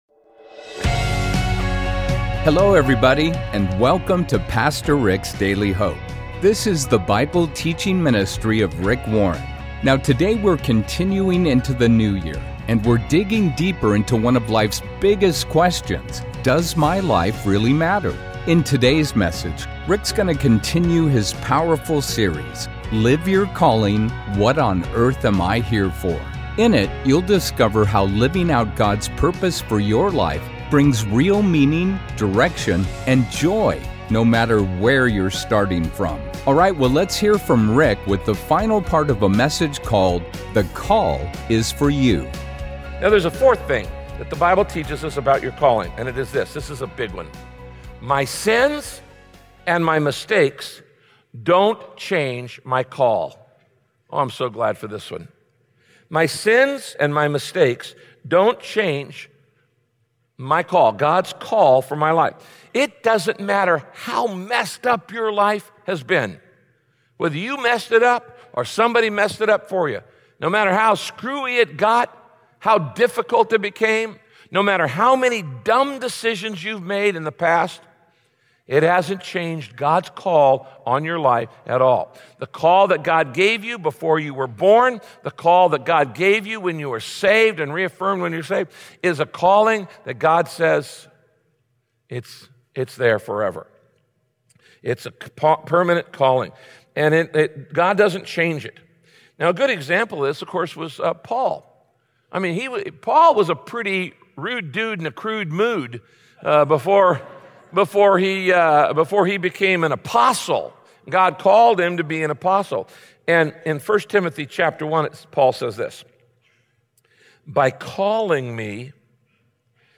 In this message, Pastor Rick explains how you…